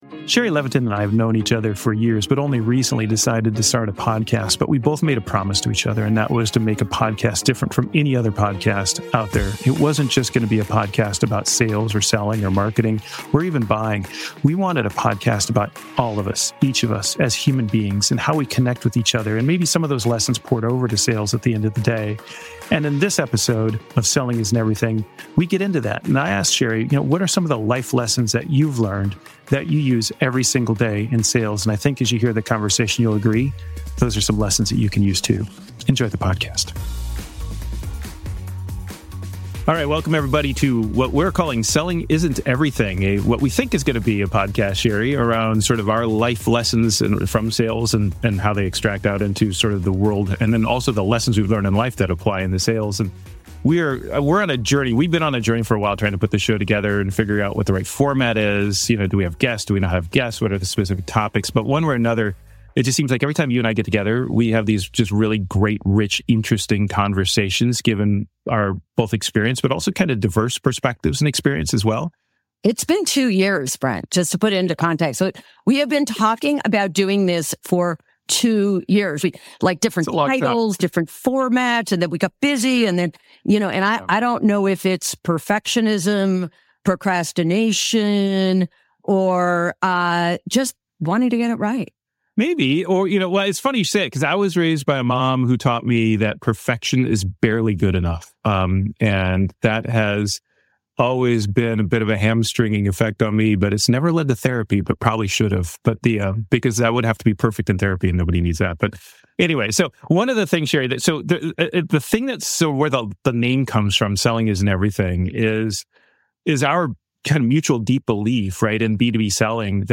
This conversation is a masterclass in the art of genuine human connection.